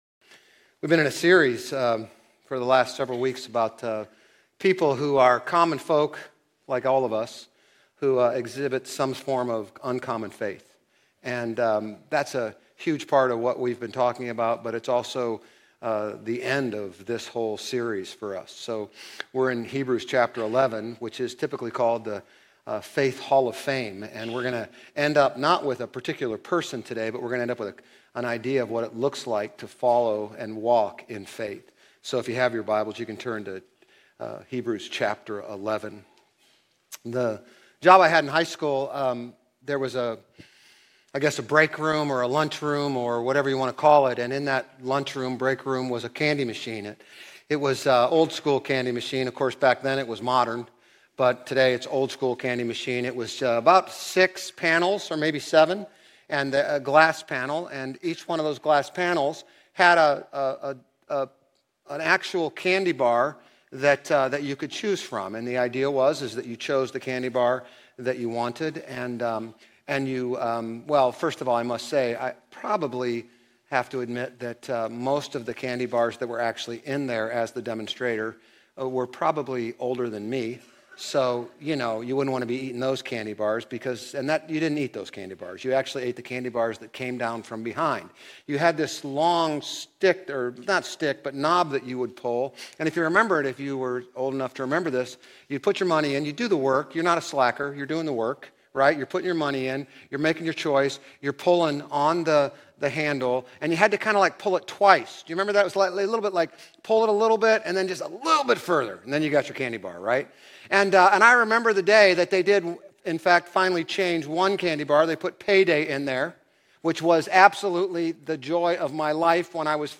Grace Community Church Old Jacksonville Campus Sermons 8_3 Old Jacksonville Campus Aug 04 2025 | 00:40:10 1x 00:00 / 00:40:10 Subscribe Share RSS Feed Share Link Embed